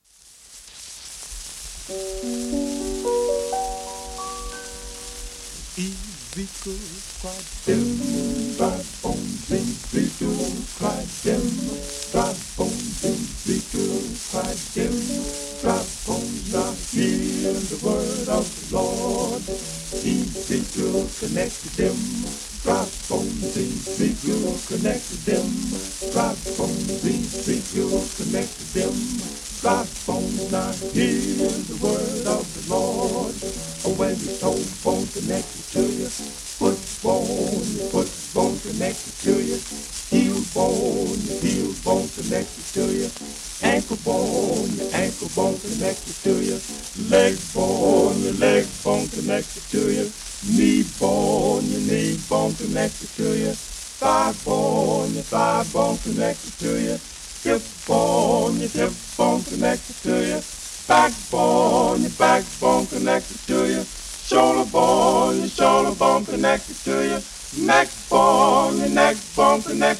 盤質B/B+ *面スレによるサーフフェイスノイズ有
1941年録音